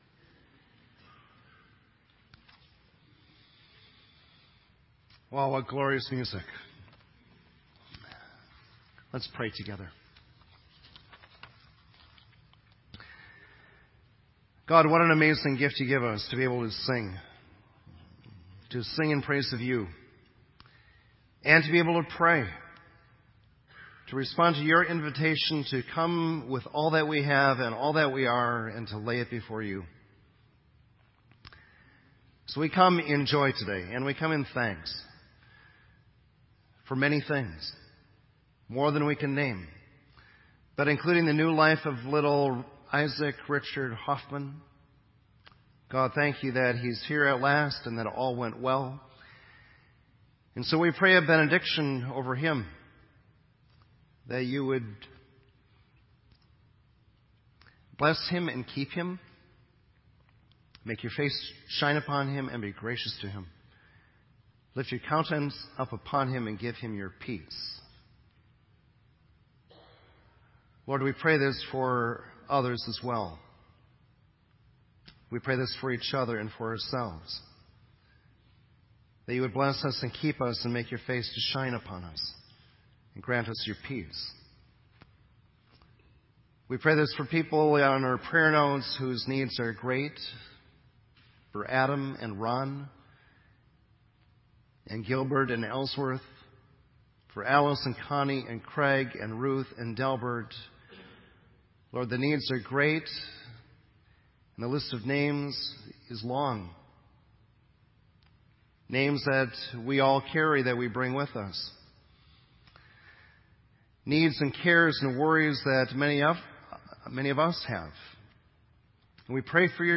Festival of Lessons & Carols Meditation
Sermon Audio